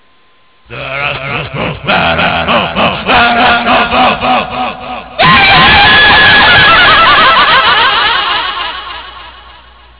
screaming